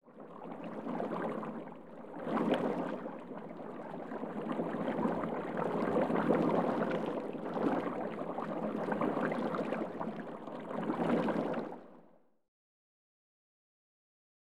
11 Water Bubbling Movement, With Per.wav